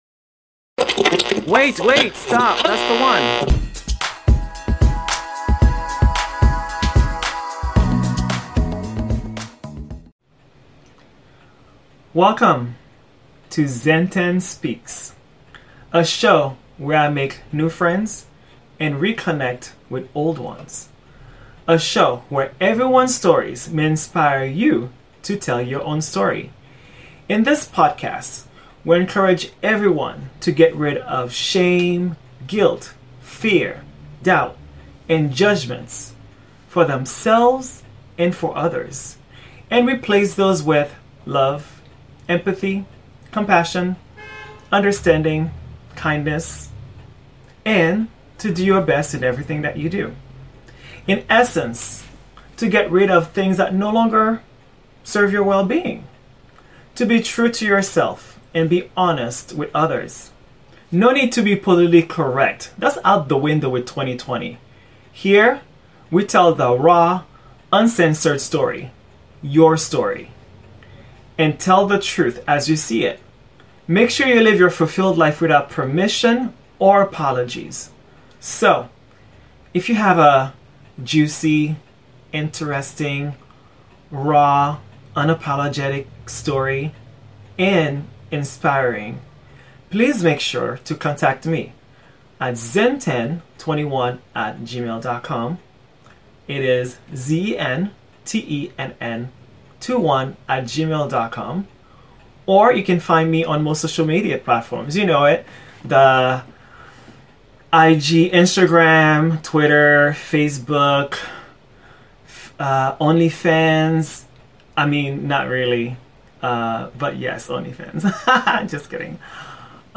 He and I chat as if we knew each other for a while.